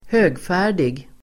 Ladda ner uttalet
högfärdig adjektiv, conceited , proud Uttal: [²h'ö:gfä:r_dig (el. ²h'ök:-)] Böjningar: högfärdigt, högfärdiga Synonymer: mallig, stroppig, uppblåst Definition: stolt, viktig, överlägsen, mallig (haughty, arrogant, smug, vain)